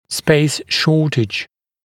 [speɪs ‘ʃɔːtɪʤ][спэйс ‘шо:тидж]недостаток места